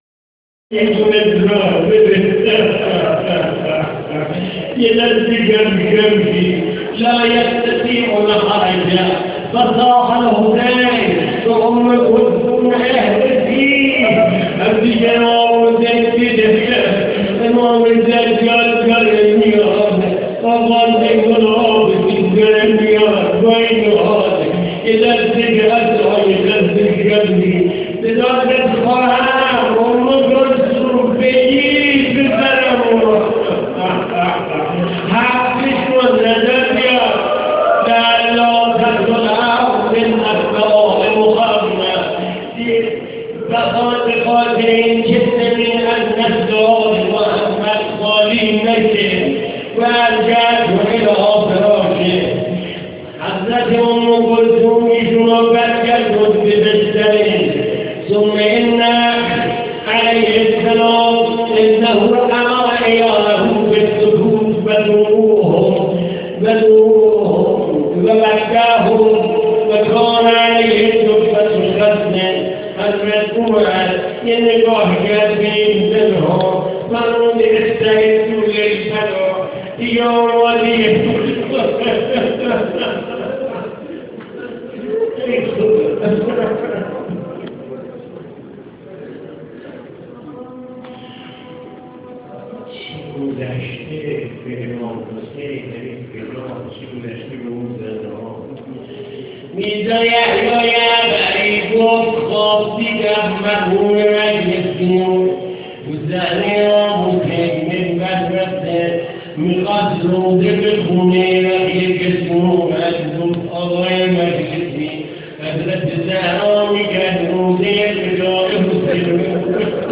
مراسم مقتل خوانی مسجد هدایت تهران
ظهر عاشورا در تهران